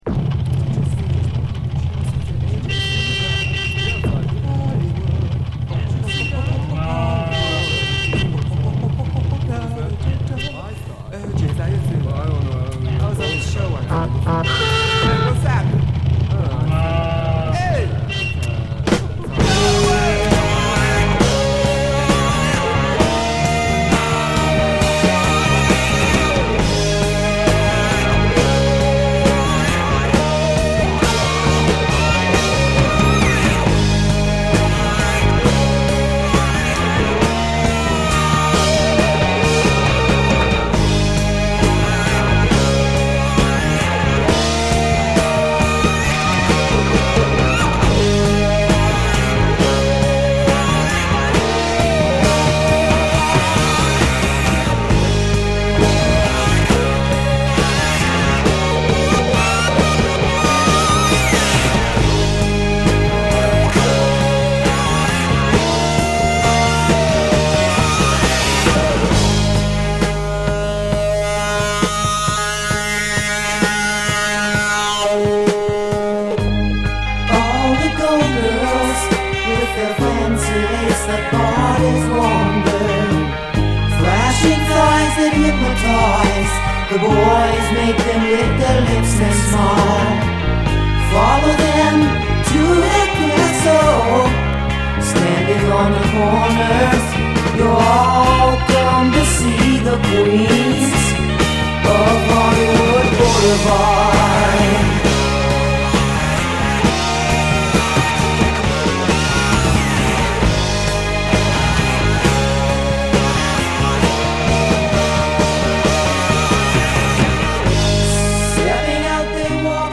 全編に渡りシンセやメロトロン等を多用した、スペイシーかつサイケデリックなぶっ飛びサウンドを貫いたカッコ良い曲を満載！